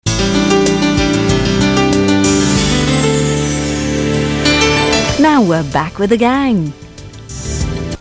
Hear an Anglia announcement over the alternative jingle, which opens with a piano rather than strings.